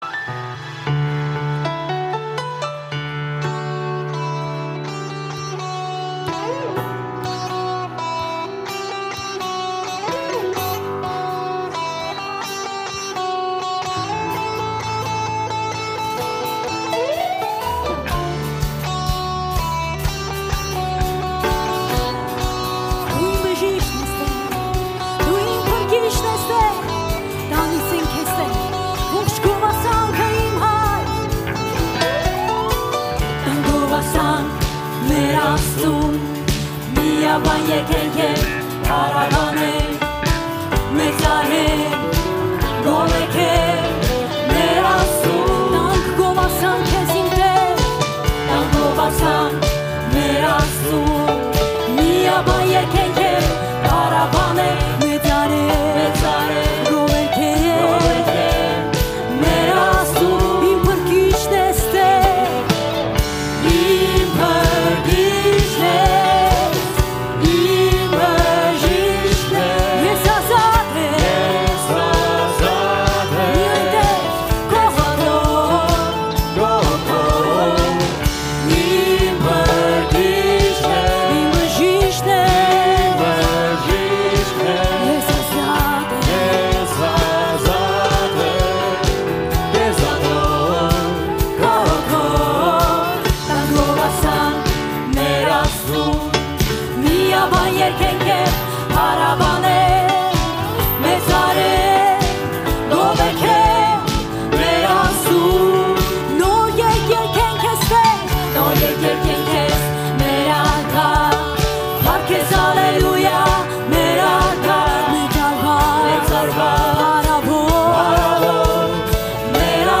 131 просмотр 240 прослушиваний 3 скачивания BPM: 125